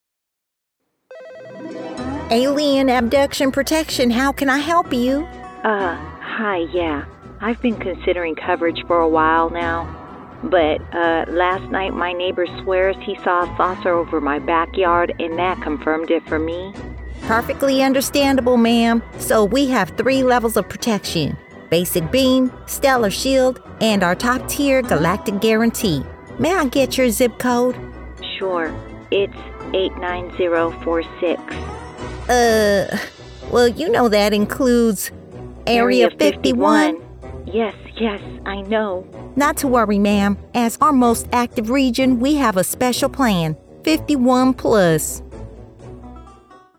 Adult (30-50)
Character, Animation, Cartoon Voices